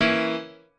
piano8_9.ogg